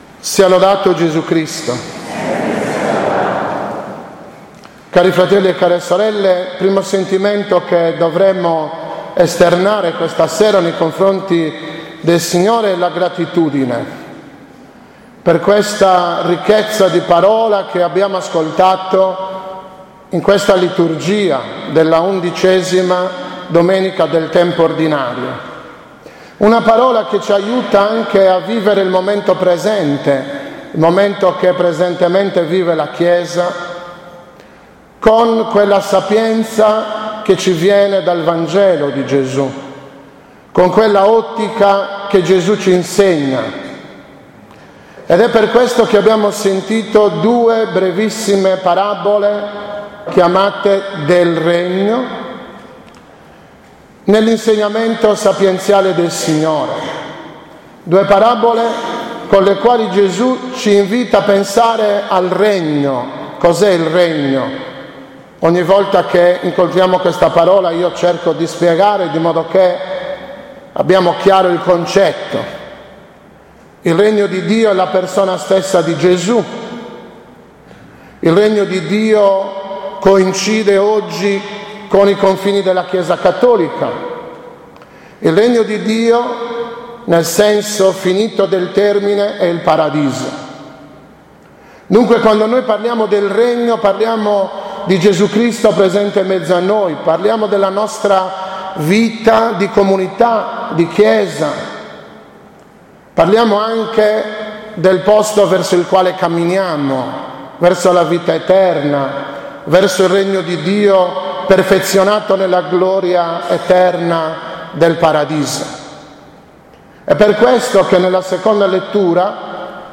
13.06.2015 – OMELIA DELLA S. MESSA PREFESTIVA DELLA XI DOMENICA DEL TEMPO ORDINARIO E MEMORIA DI S. ANTONIO DA PADOVA